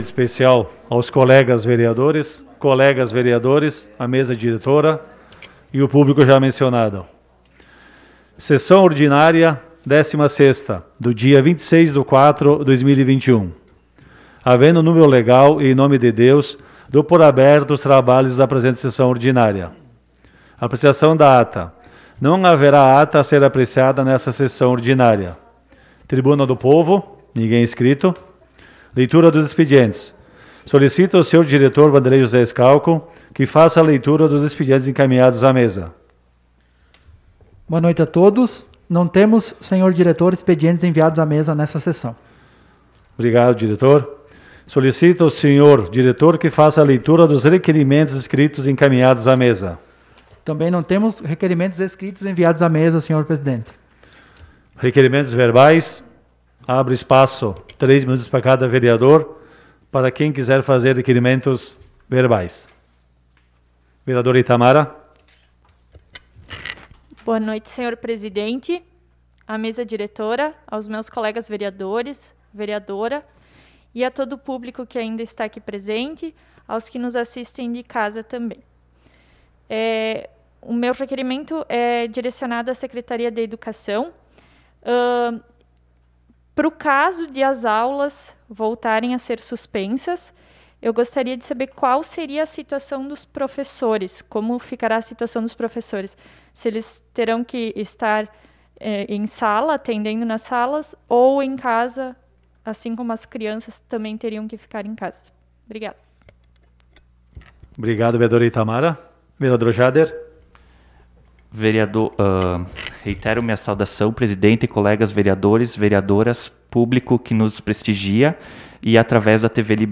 Sessão Ordinária do dia 26 de Abril de 2021 - Sessão 16